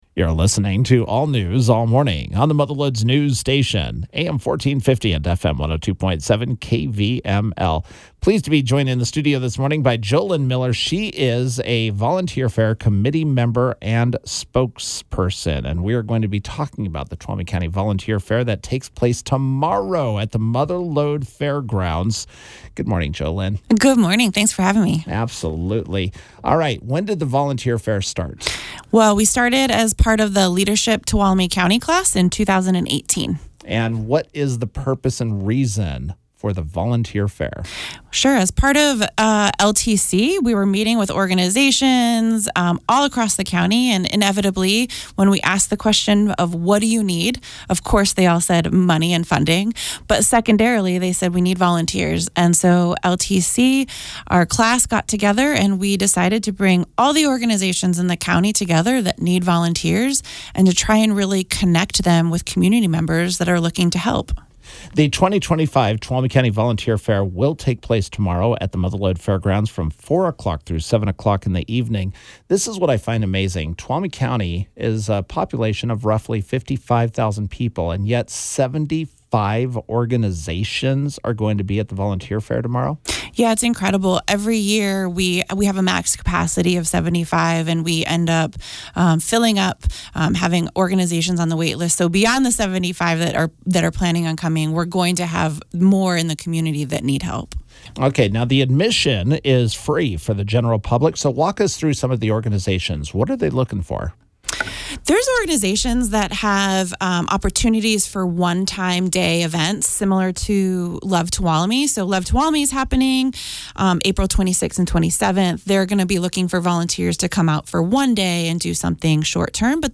Here is the interview as aired: